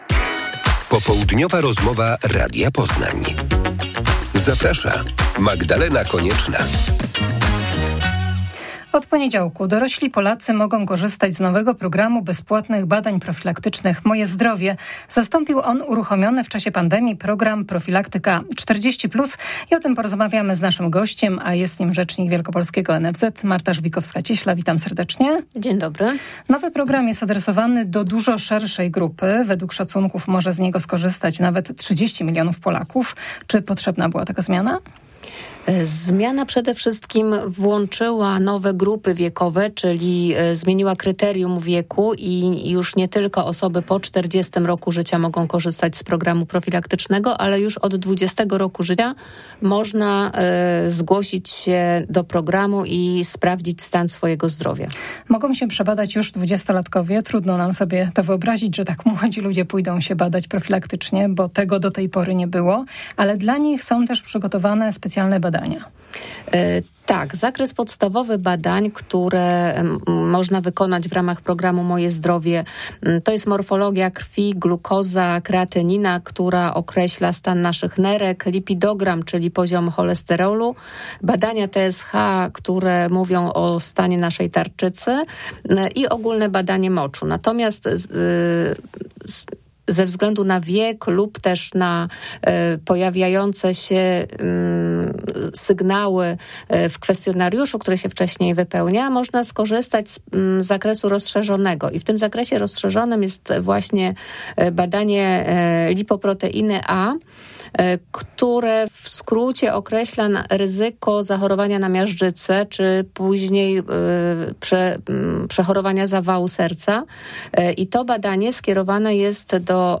Popołudniowa rozmowa Radia Poznań